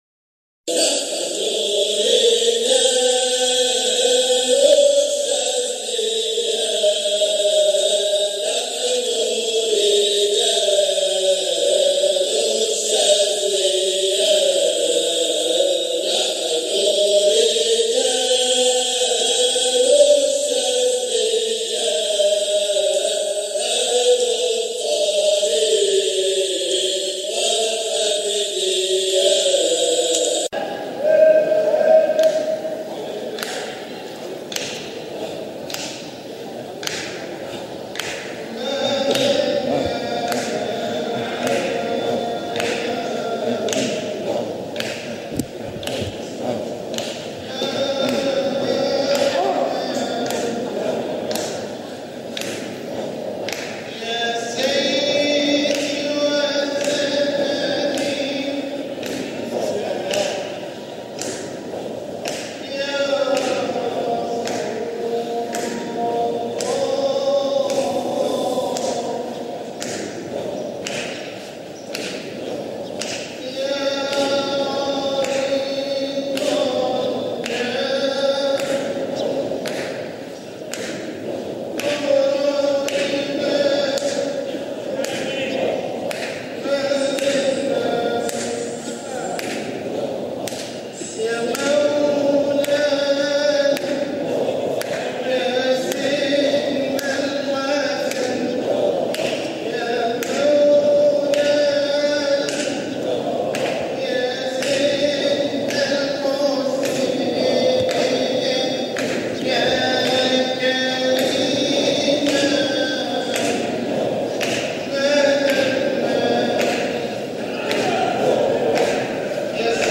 مقاطع من احتفالات ابناء الطريقة الحامدية الشاذلية بمناسباتهم
جزء من حلقة ذكر بمسجد سيدنا احمد ابى العباس المرسى قُدس سره 2017